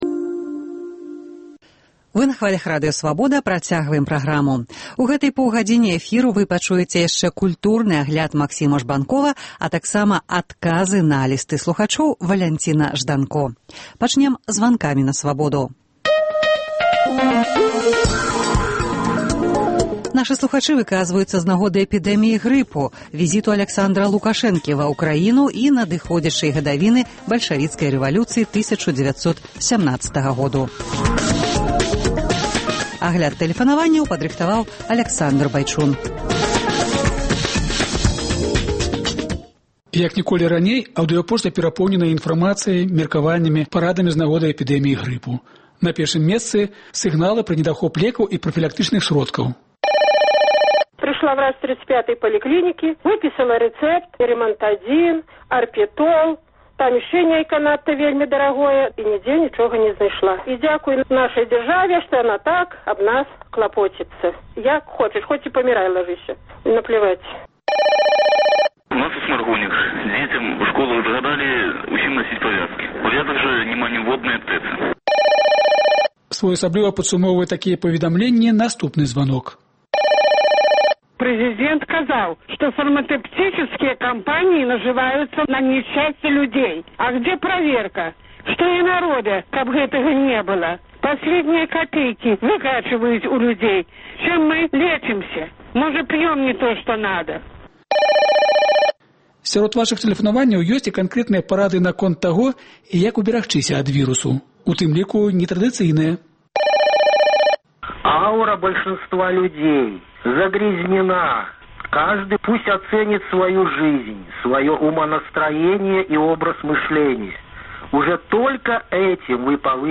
Званкі на Свабоду
У сёньняшніх “Званках на Свабоду” слухачы выказваюцца з нагоды эпідэміі грыпу, візыту Аляксандра Лукашэнкі ва Ўкраіну, надыходзячай гадавіны бальшавіцкай рэвалюцыі 1917-га году ў Расеі.